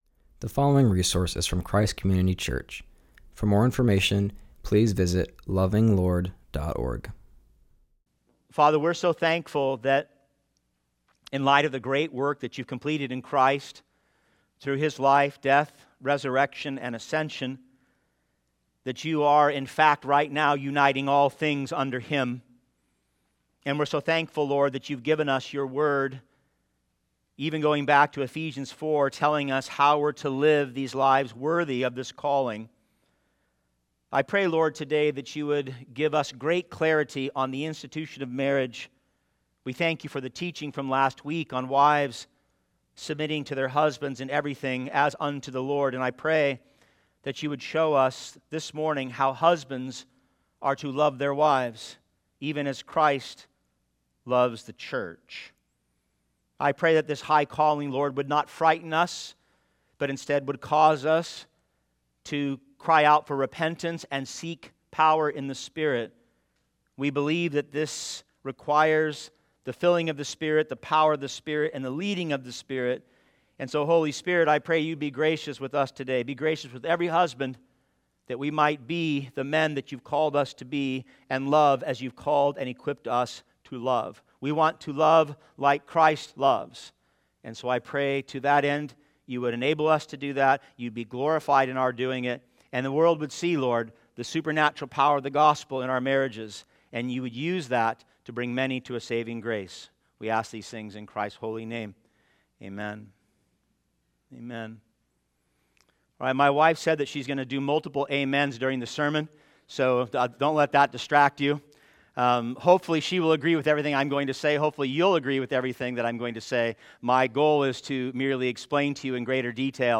continues our series and preaches from Ephesians 5:22-33.